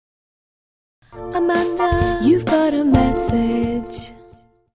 SMS Name Tone